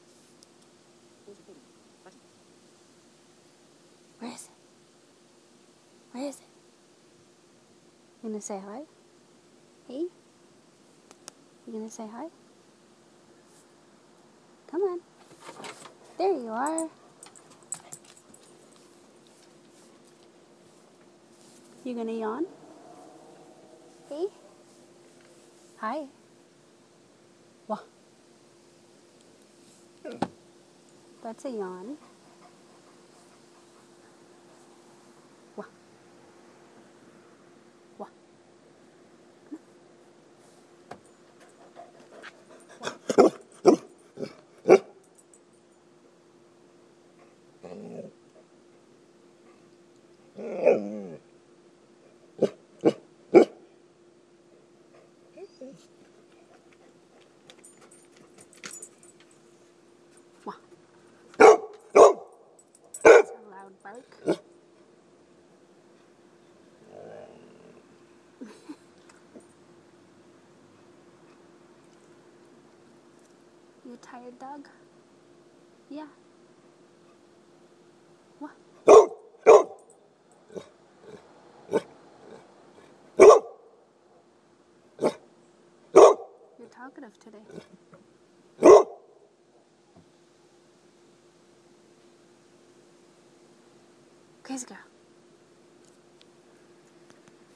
Big barks